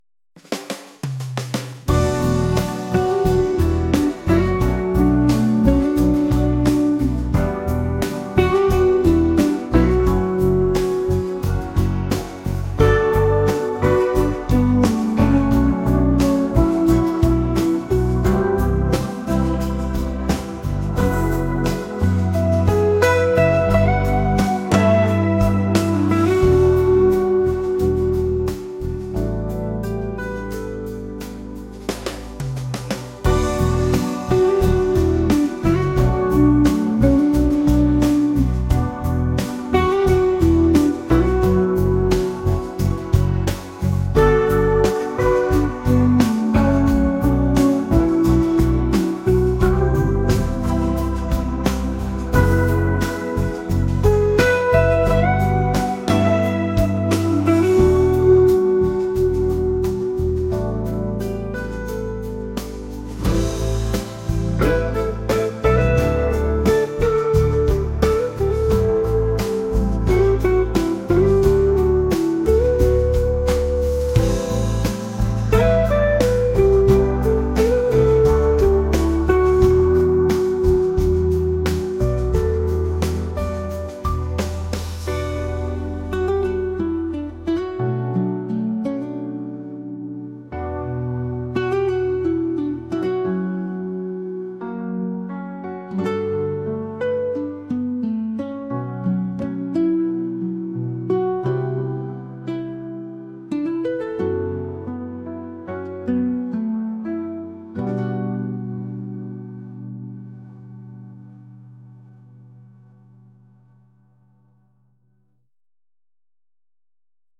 pop | soulful | laid-back